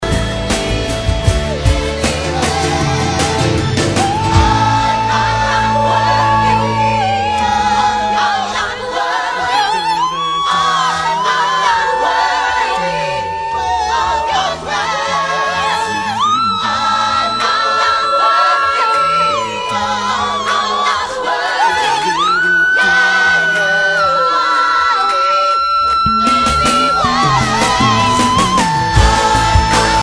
high notes...